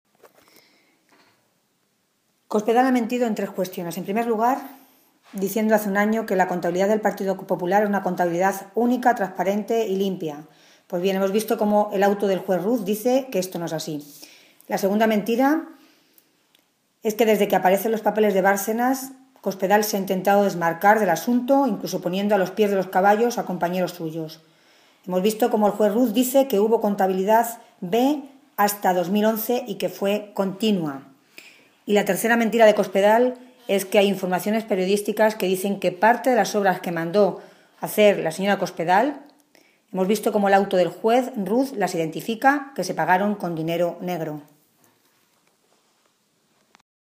Tolón se pronunciaba de esta manera esta mañana en Toledo, en una comparecencia ante los medios de comunicación en la que hacía referencia al auto, conocido ayer, del juez de la Audiencia Nacional, Pablo Ruz, que establece indicios claros de que el PP ha estado manteniendo en el tiempo una doble contabilidad o contabilidad B y amplía el periodo a investigar hasta el año 2011, cuando Cospedal llevaba ya años siendo la número dos de este partido en España y después de que, según ella, los populares hubieran apartado a su ex tesorero, Luis Bárcenas, de cualquier responsabilidad.
Cortes de audio de la rueda de prensa